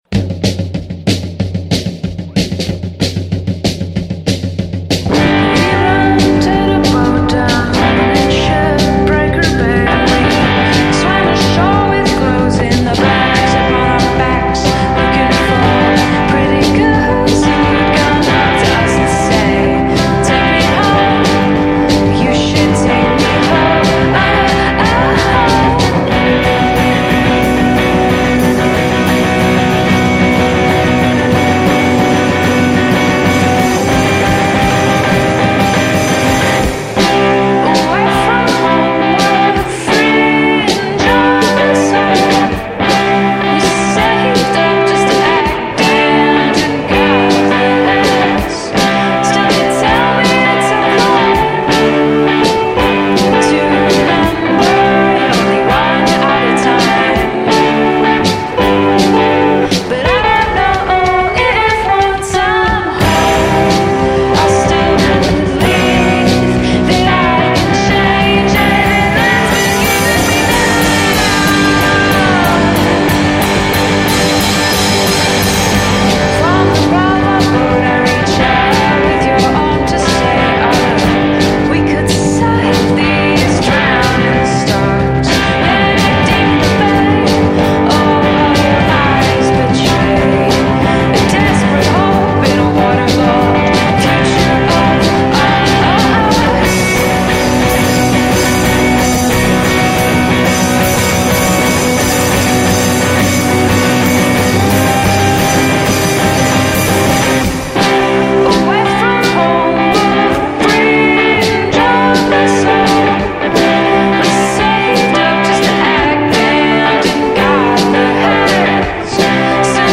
first stellar demo
indie rock band
drums & guitar
bass